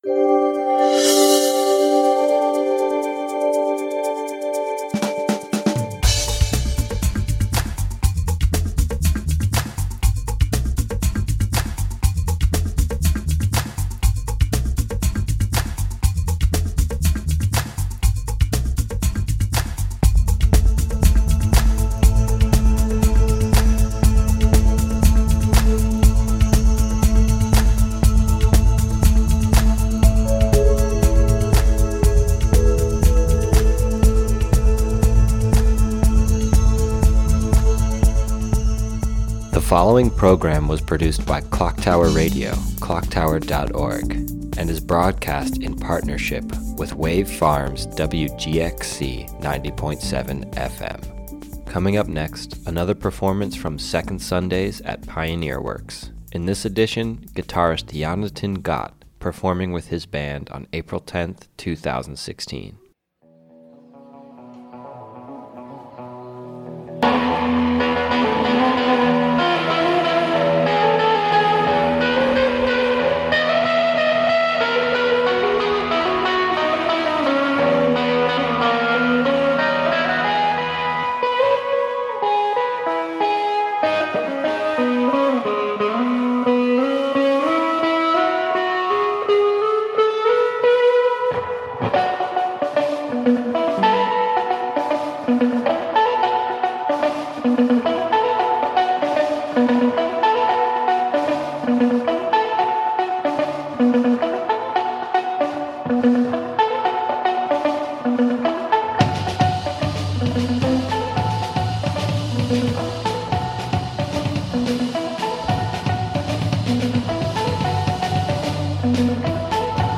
signature psychedelic solos
recorded live in concert
minimalist and truly psychedelic whirlwind of notes